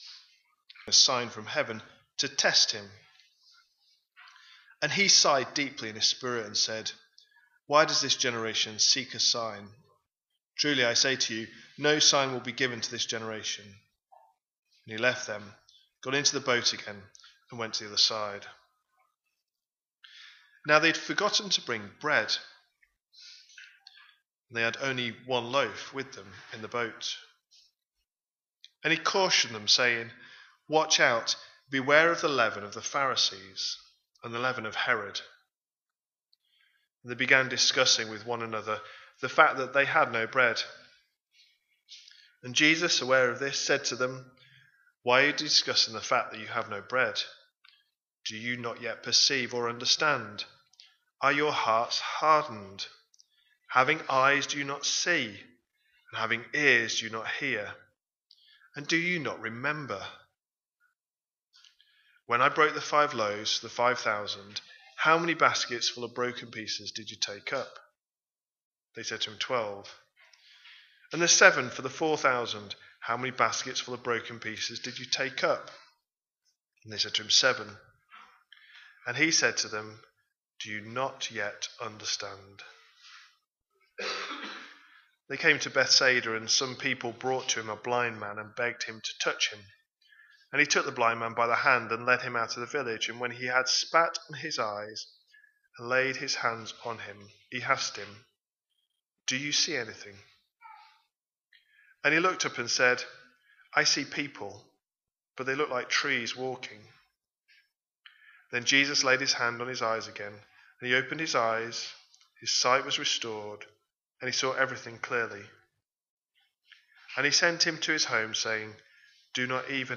A sermon preached on 7th December, 2025, as part of our Mark 25/26 series.